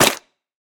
snapshot / assets / minecraft / sounds / block / muddy_mangrove_roots / step1.ogg